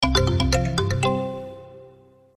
ring1.mp3